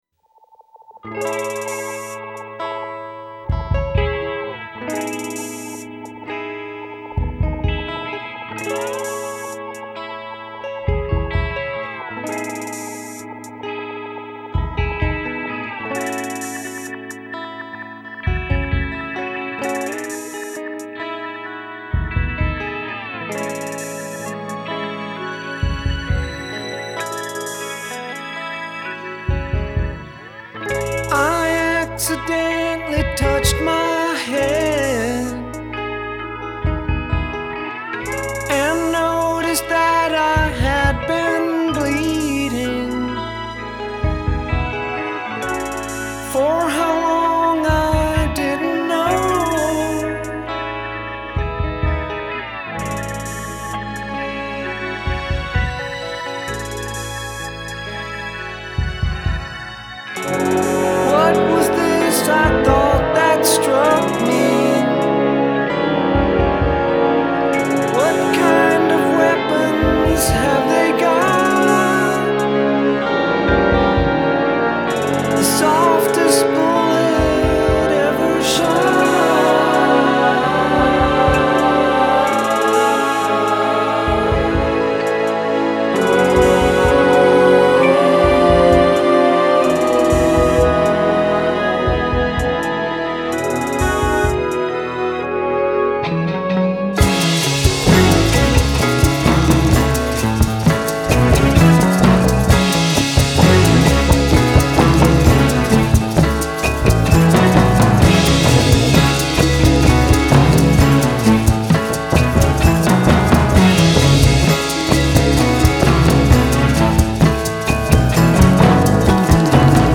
Жанр: neo-psychedelia, art rock, dream pop